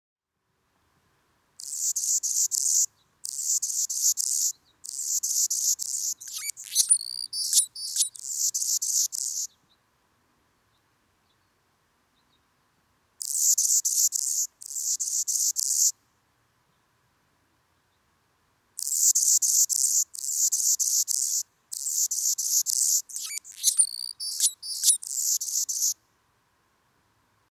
Звук с пением птицы Калипта Анны из семейства колибри